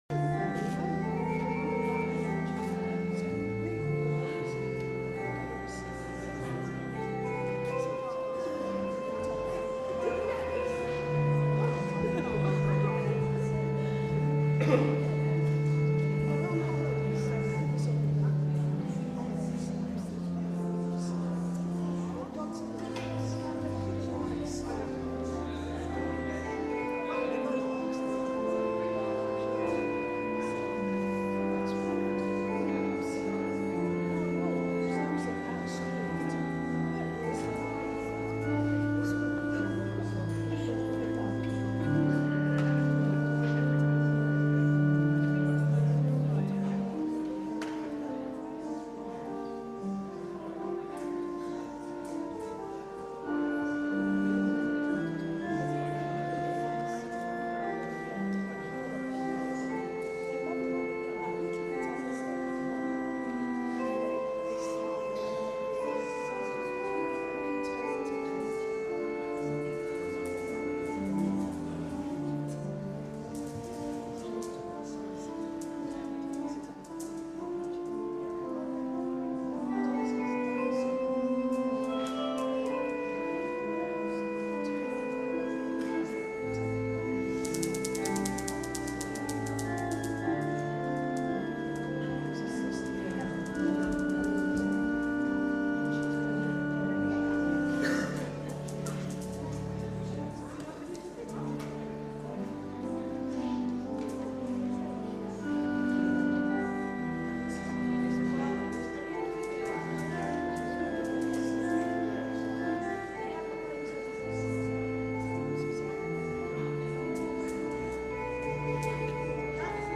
A collection of Podcasts from Wesley Memorial Church preachers.